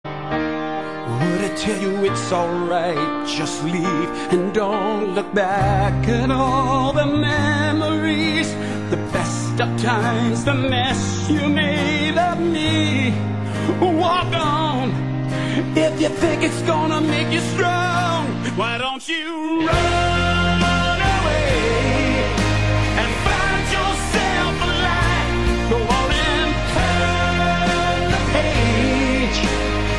opera-like voice and sound